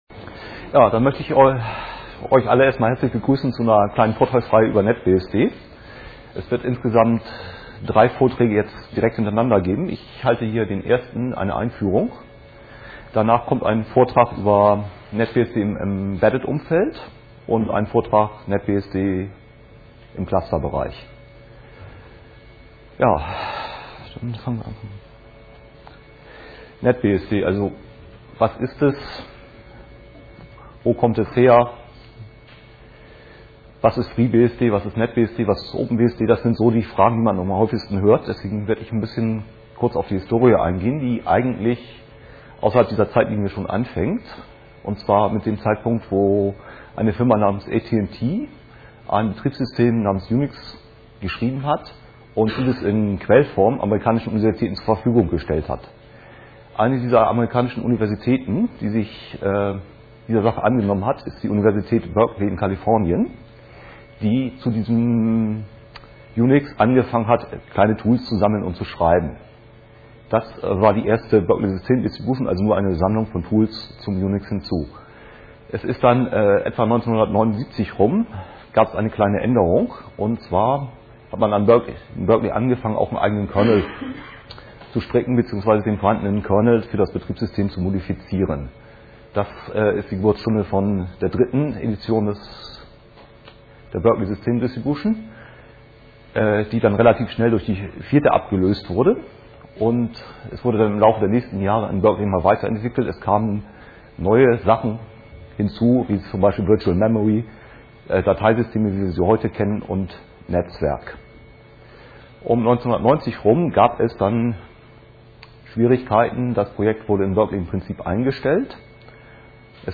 5. Chemnitzer Linux-Tag
Sonntag, 14:00 Uhr im Raum V1 - NetBSD